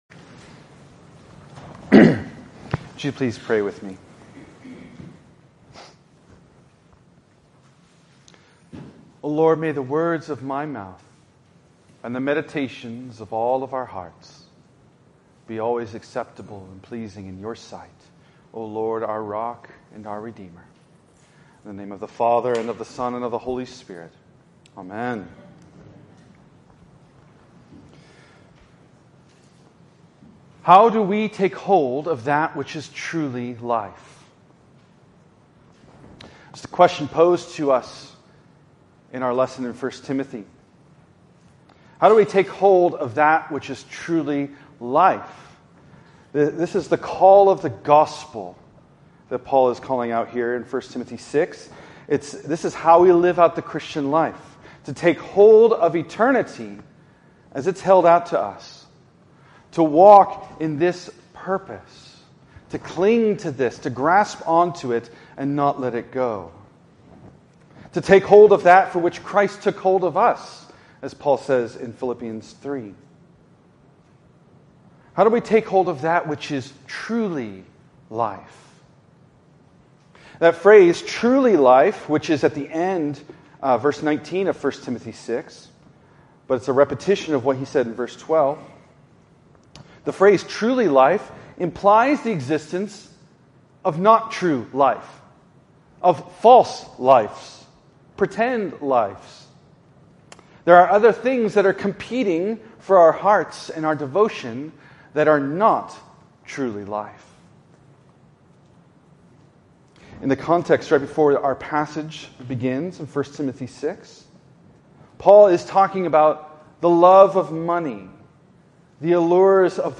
Show Video Player Save Audio In this sermon on the 16th Sunday after Pentecost